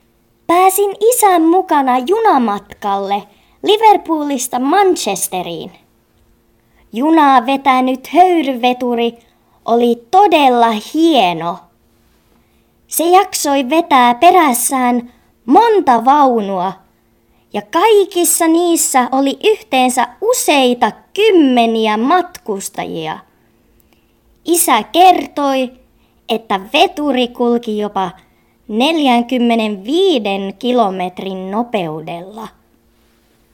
HÖYRYVETURIN ÄÄNI
Veturi1.mp3